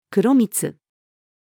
黒みつ-female.mp3